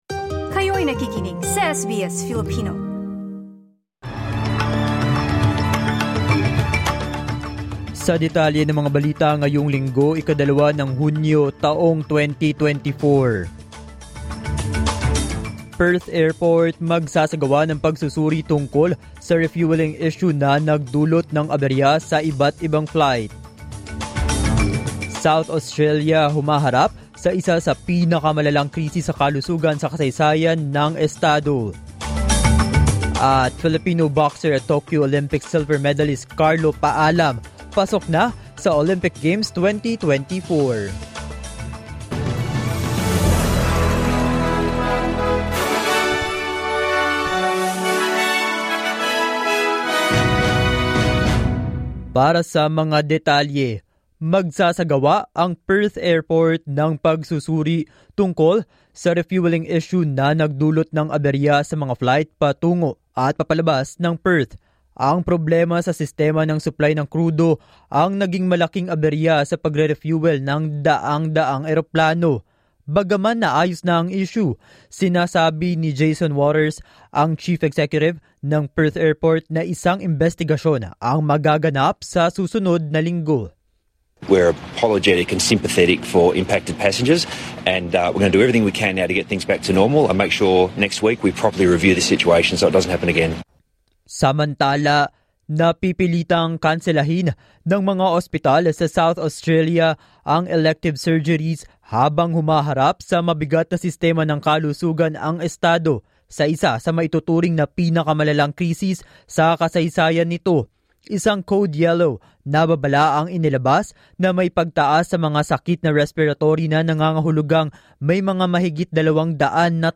SBS News in Filipino, Sunday 2 June 2024